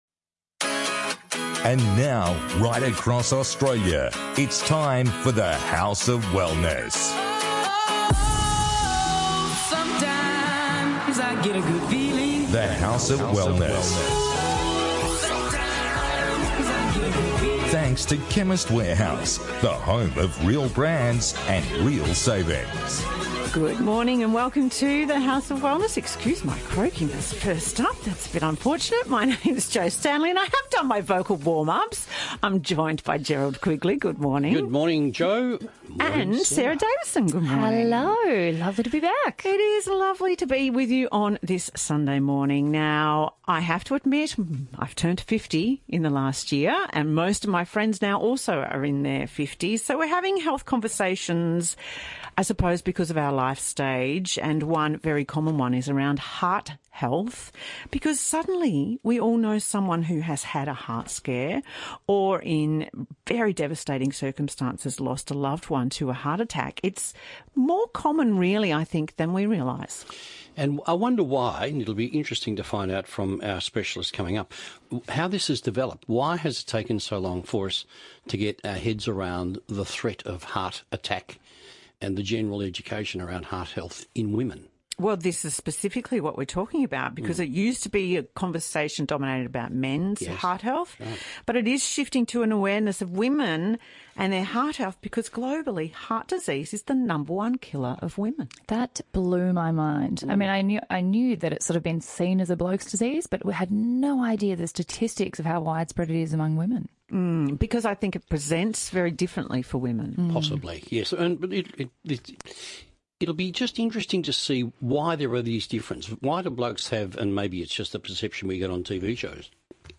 On this week’s The House of Wellness radio show the team discusses: